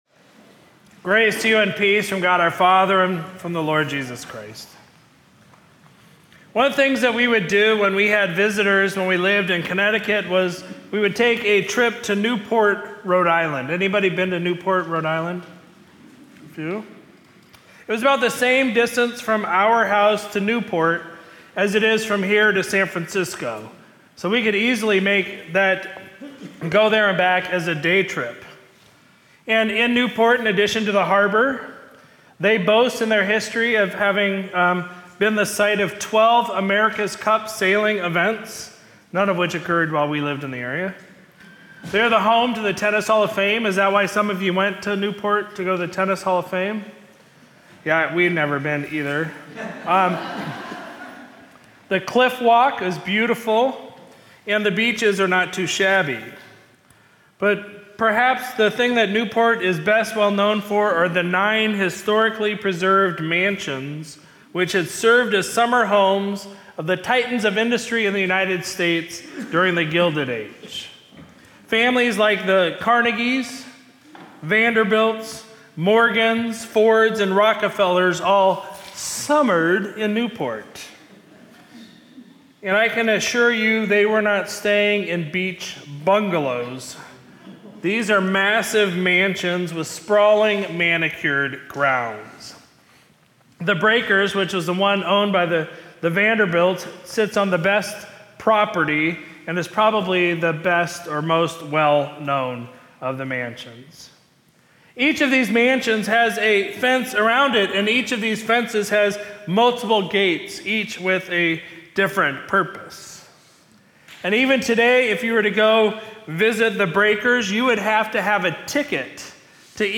Sermon from Sunday, March 30, 2025